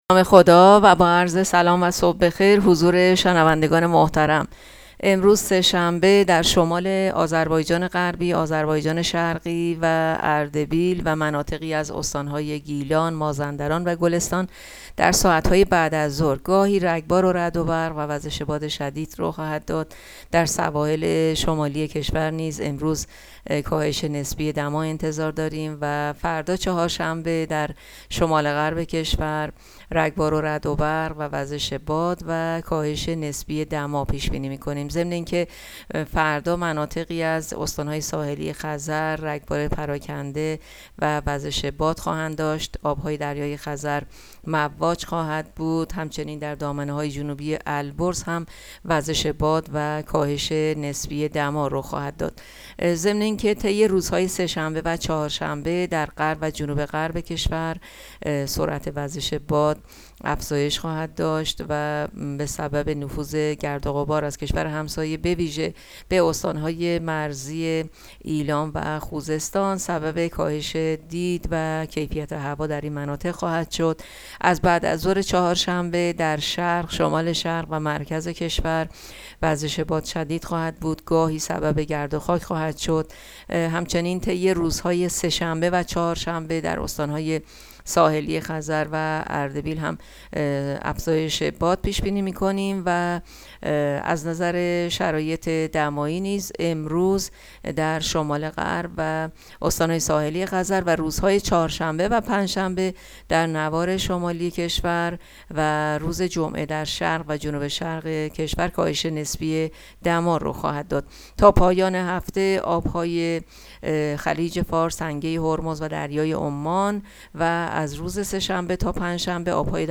گزارش رادیو اینترنتی پایگاه‌ خبری از آخرین وضعیت آب‌وهوای ۱۳ خرداد؛